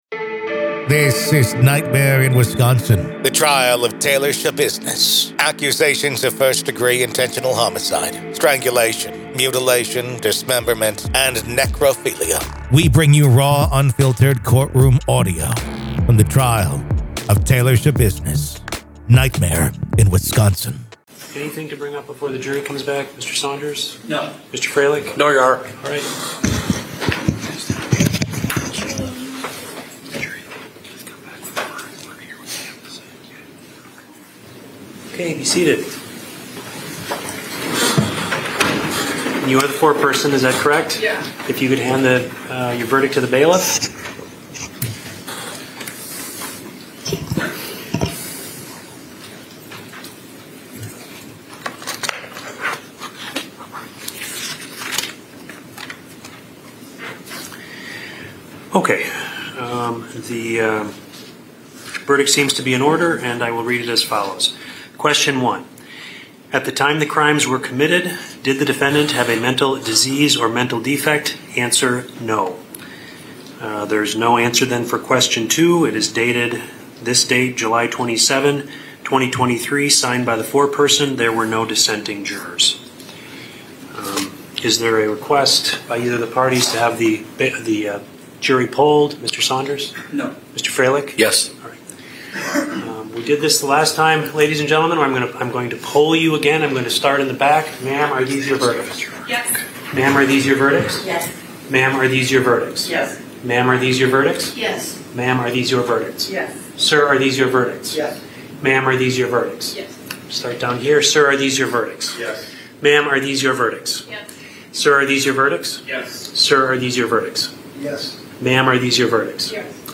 Raw courtroom audio from the hearing and trial